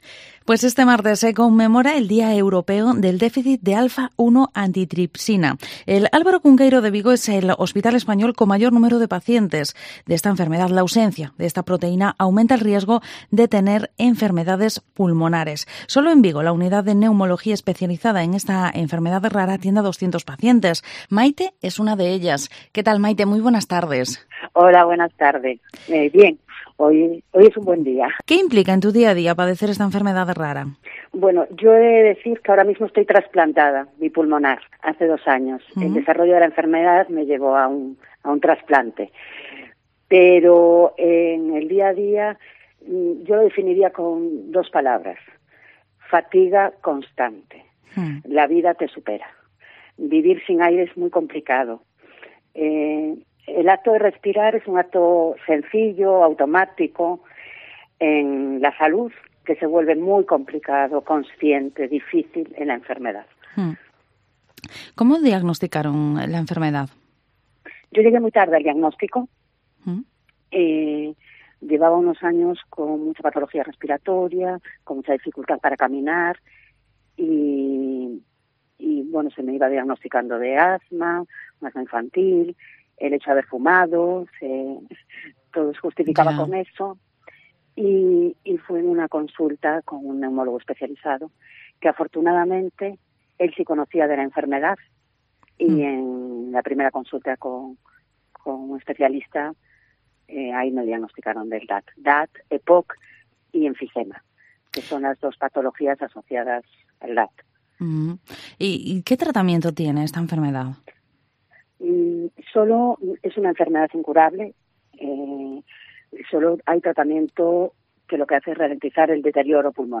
Vigo Entrevista Día Europeo del Déficit de Alfa-1 Antitripsina.